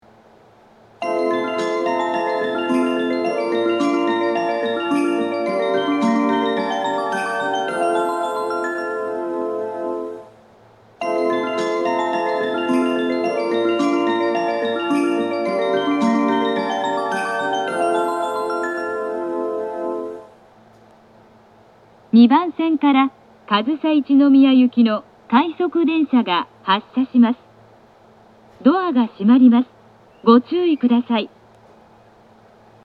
音質が大変良いです。
発車メロディー 2コーラスです!時間調整が少なく、1番線より鳴りにくいです。